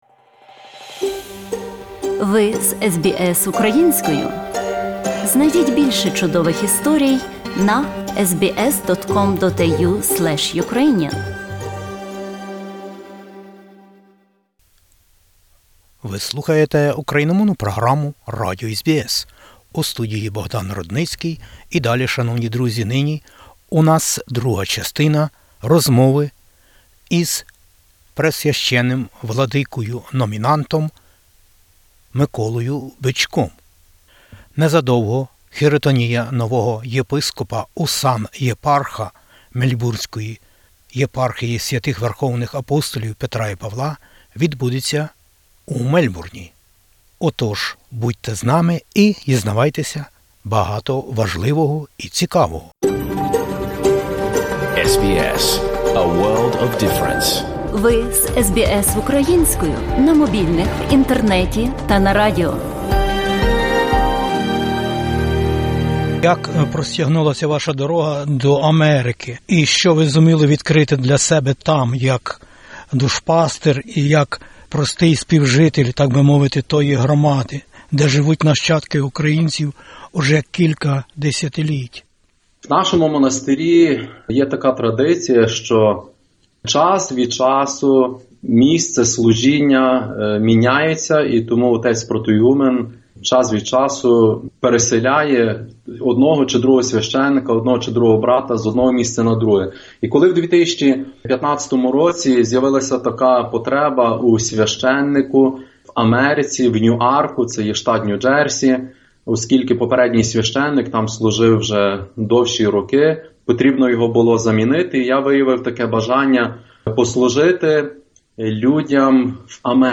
SBS Ukrainian інтерв'ю. Це сталося 15 січня 2020 року у Ватикані. Св. Отець Папа Франциск задовільнив вибір Синоду УГКЦ і поблагословив нового главу Мельбурнської єпархії Свв. Верх. Апп. Петра і Павла УГКЦ в Австралії, Новій Зеландії та країнах Океанії.